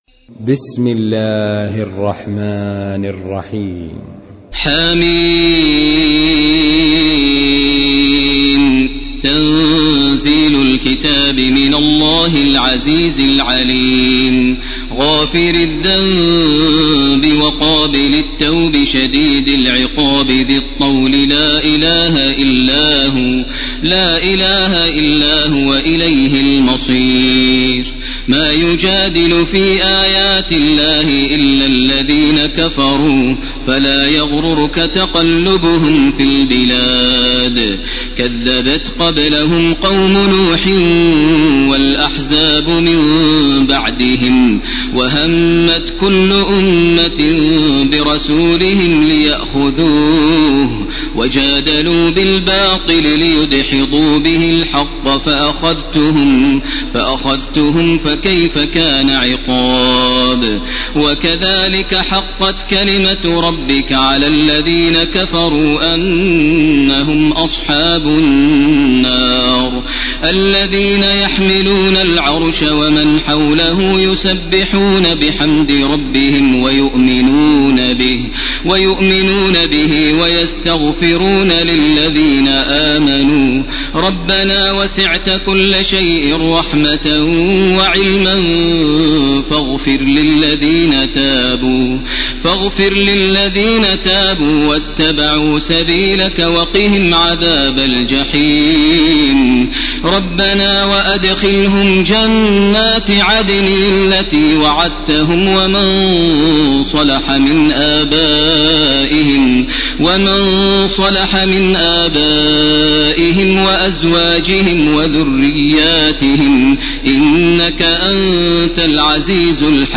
تراويح ليلة 23 رمضان 1431هـ سورة غافر كاملة Taraweeh 23 st night Ramadan 1431H from Surah Ghaafir > تراويح الحرم المكي عام 1431 🕋 > التراويح - تلاوات الحرمين